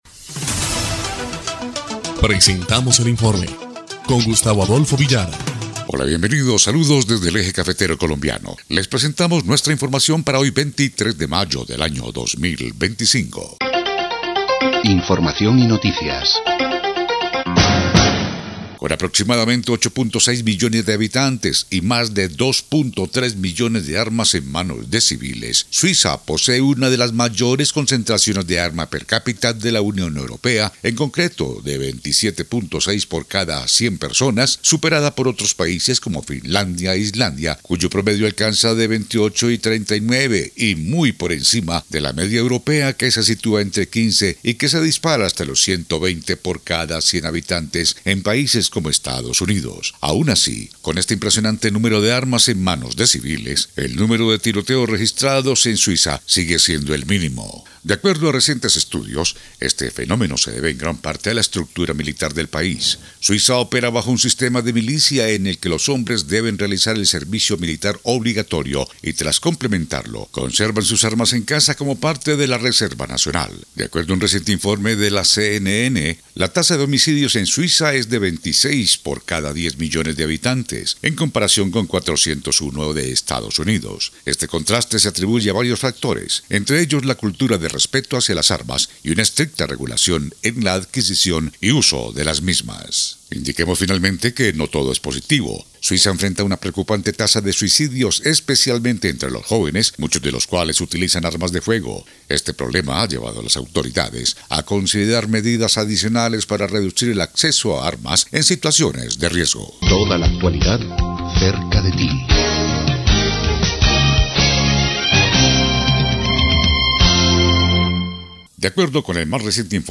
EL INFORME 3° Clip de Noticias del 23 de mayo de 2025